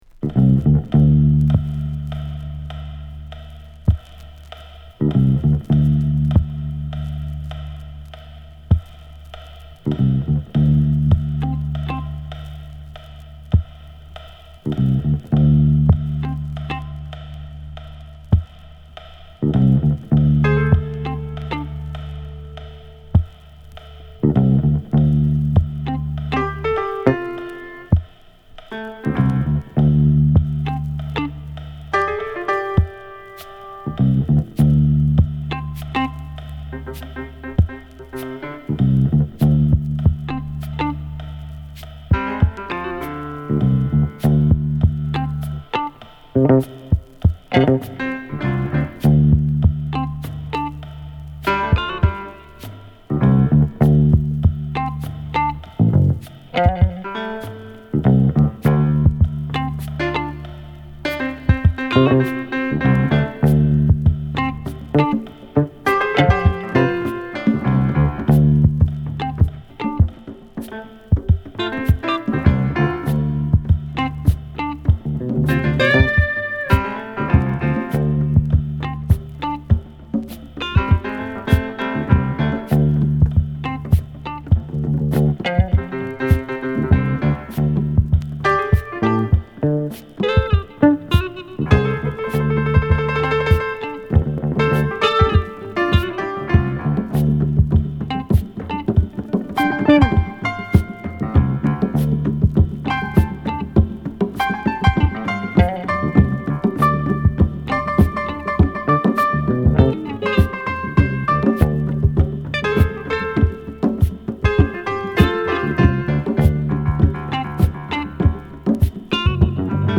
本作はパーカッション／ベース／ピアノ等、シンプルな構成をバックに、ポエトリーを乗せる1枚で